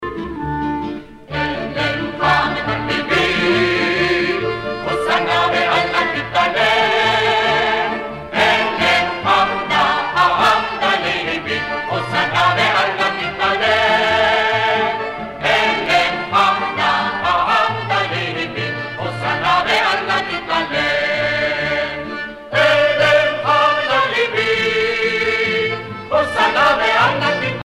Prières et chants religieux